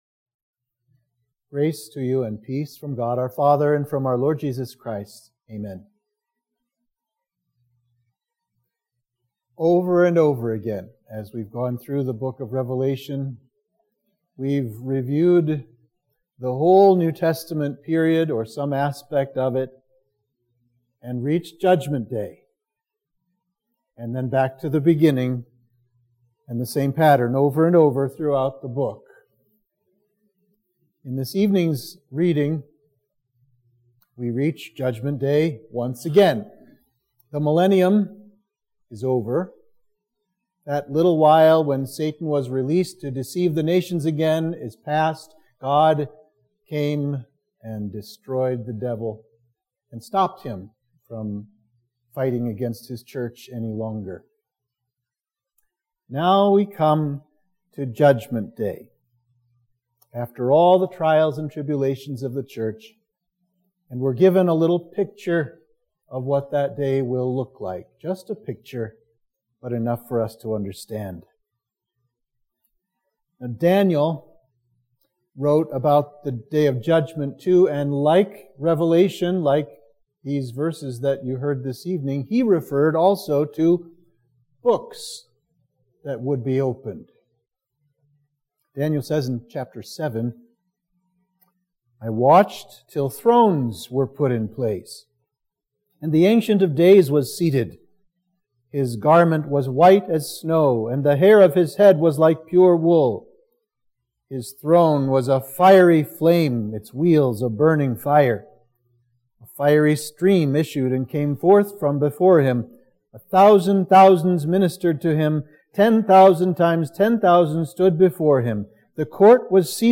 Sermon for Midweek of Trinity 20